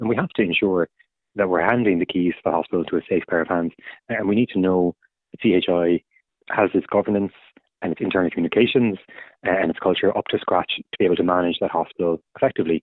Committee chair and Social Democrats TD, Pádraig Rice says CHI have to address a number of recent scandals first…………